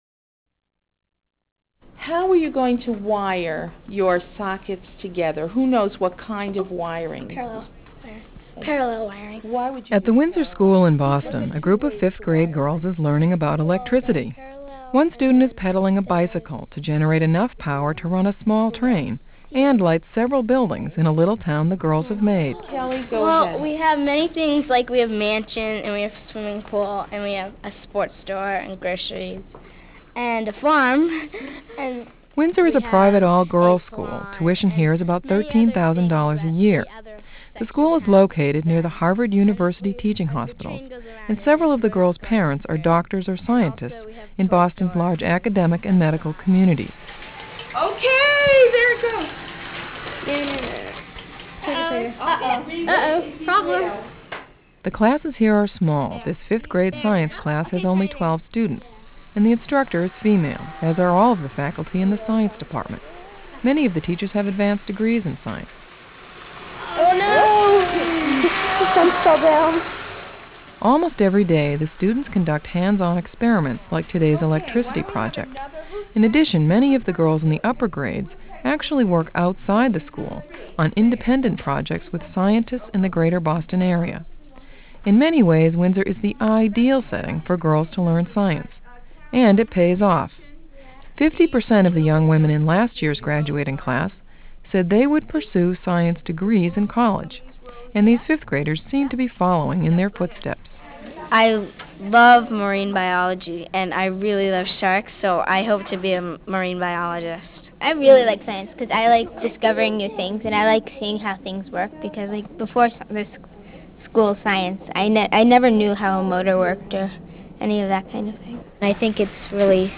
This report was produced by Monitor Radio and originally broadcast in May of 1993.